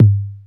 • Boom Bass Drum G Key 120.wav
Royality free kick sound tuned to the G note. Loudest frequency: 137Hz
boom-bass-drum-g-key-120-4fV.wav